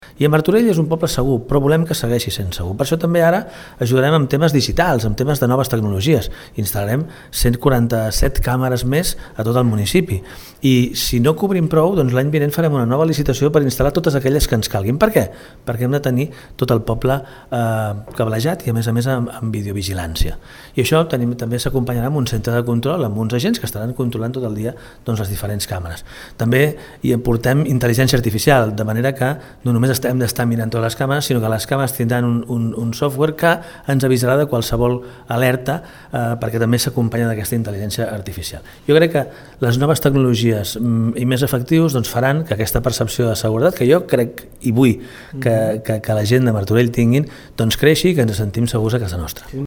Xavier Fonollosa, alcalde de Martorell